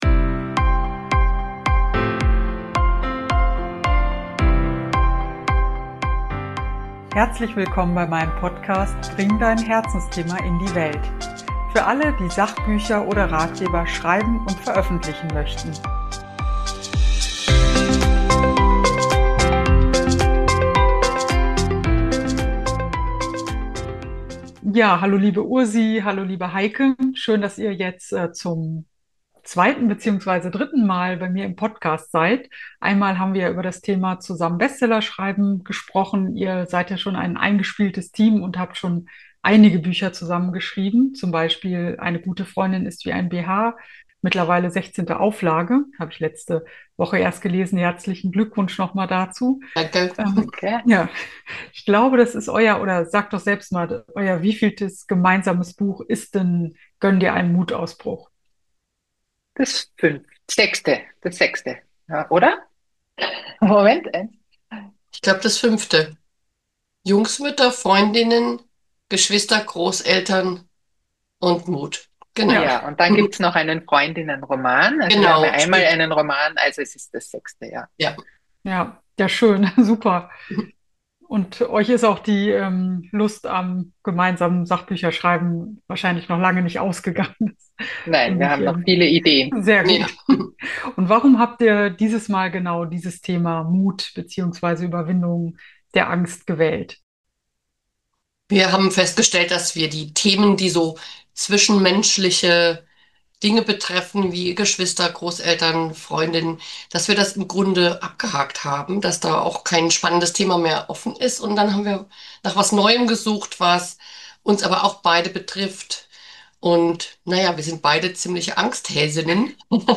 Gönn dir einen Mutausbruch - Interview